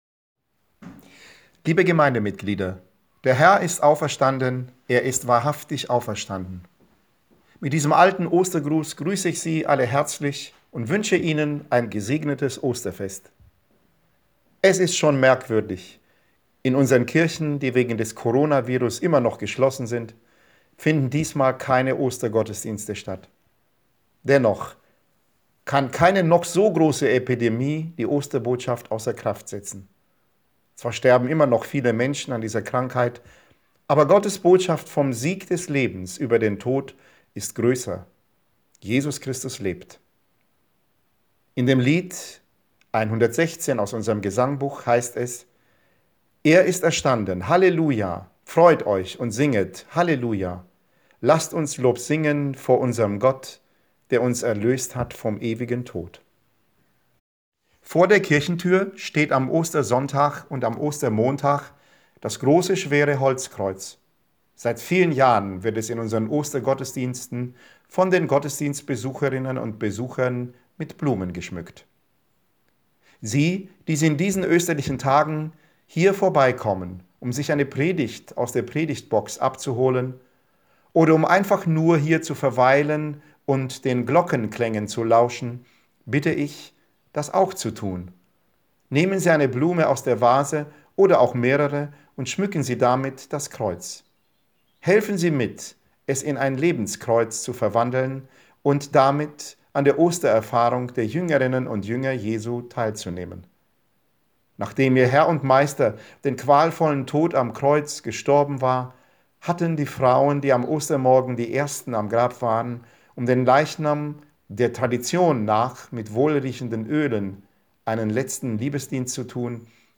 Osterpredigt 2020
Osterpredigt 2020.mp3